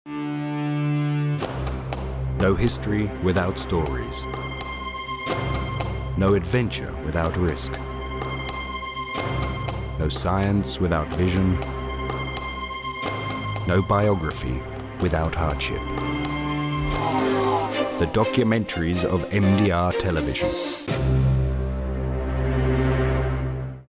Sprecher englisch uk.
Sprechprobe: Industrie (Muttersprache):